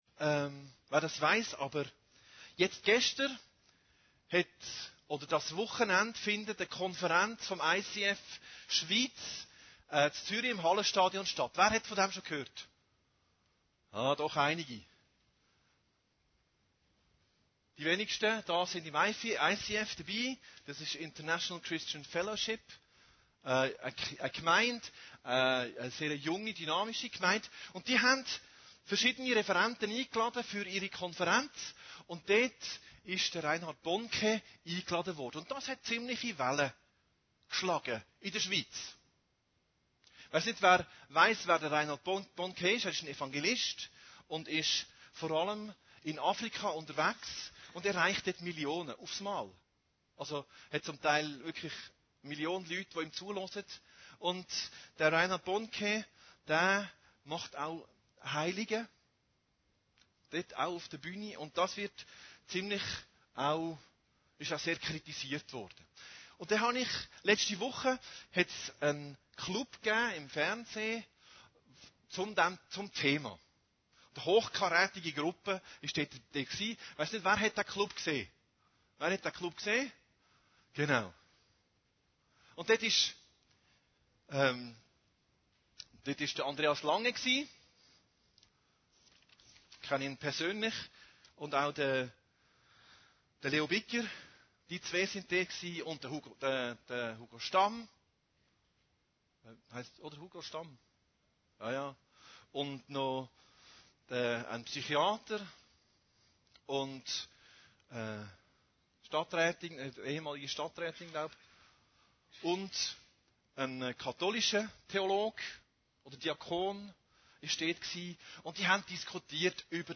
Predigten Heilsarmee Aargau Süd – Die Kraft des Heiligen Geistes